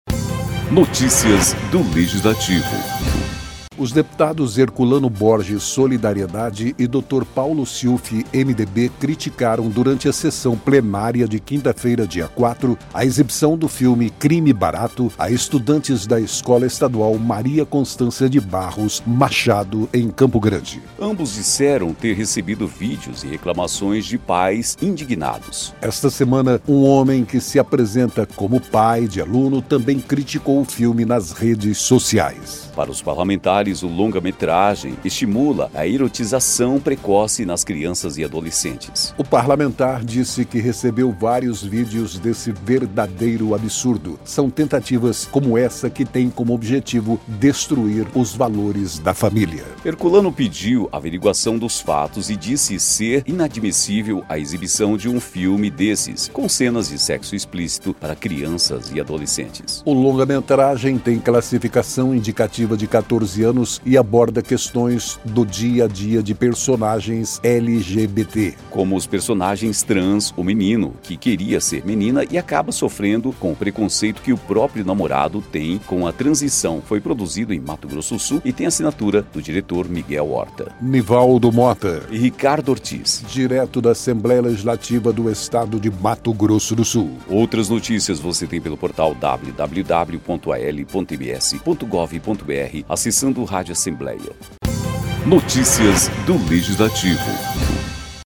Os deputados Herculano Borges (SD) e Dr. Paulo Siufi (MDB) criticaram, durante a sessão plenária desta quinta-feira (4), a exibição do filme Crime Barato a estudantes da Escola Estadual Maria Constança de Barros Machado, em Campo Grande.